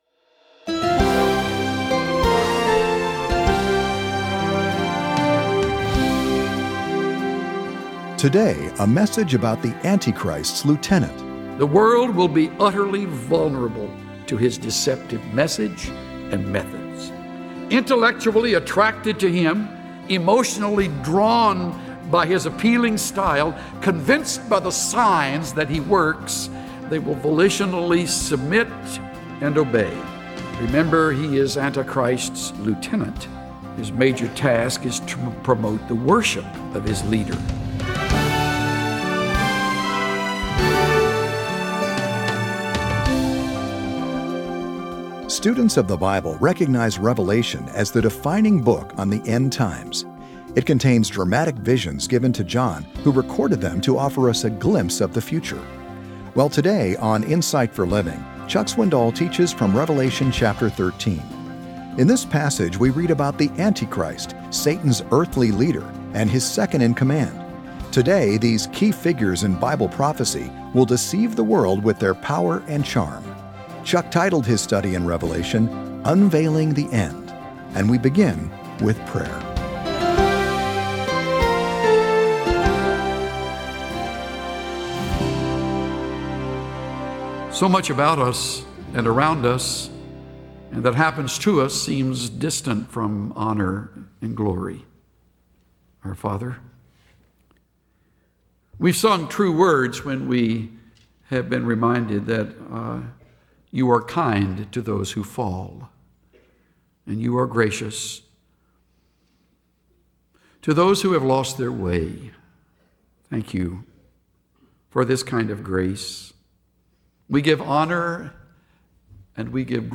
Insight for Living Daily Broadcast